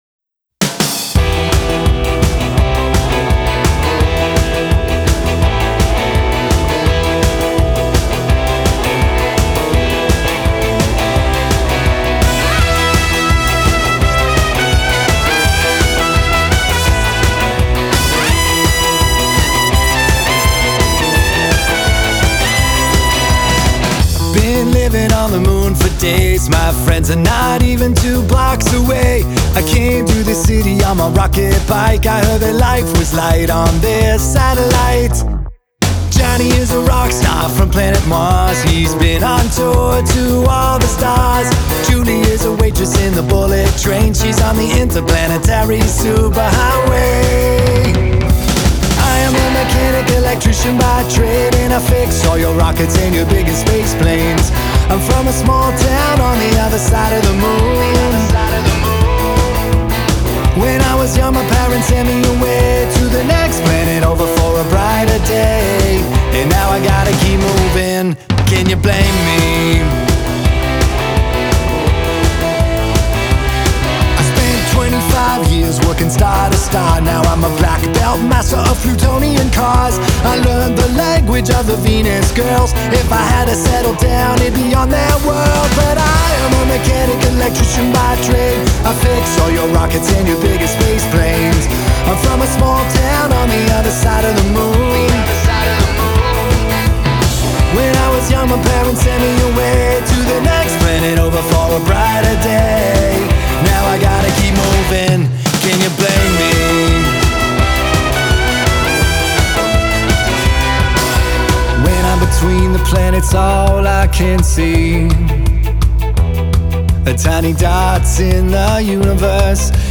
Keywords: Rock, Indie, Alternative, Punk, Trumpet
145 bpm
vocals, trumpet
drums
bass
guitars